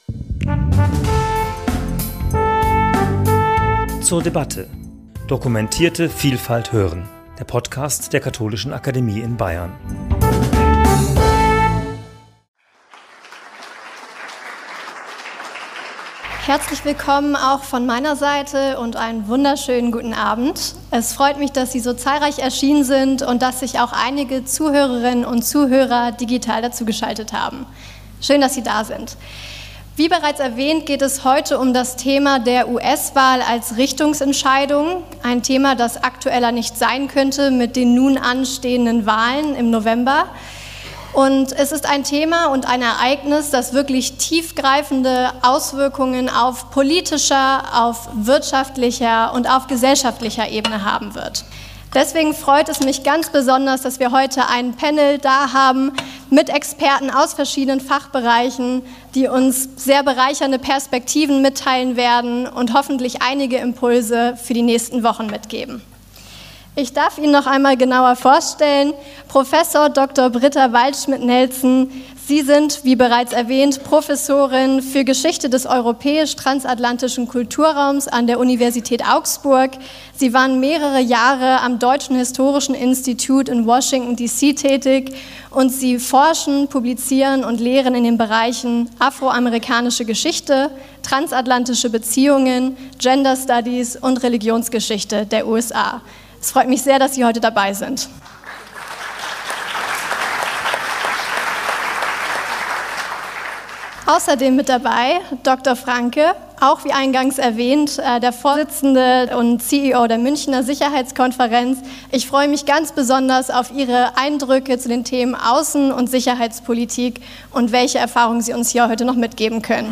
Podium zum Thema 'Harris vs. Trump - Die US-Wahlen als Richtungsentscheidung' ~ zur debatte Podcast
In der Diskussion zwischen USA-Expertinnen und -Experten wurden Einschätzungen und Prognosen zum Ausgang der Wahl und zu deren Auswirkungen vorgenommen. Dabei ging es vor allem um die Bereiche Politik, Sicherheit und Verteidigung sowie Wirtschaft. Gleichzeitig wurden die Bedeutung und die Konsequenzen der Wahlentscheidung für die USA, für Europa und global diskutiert.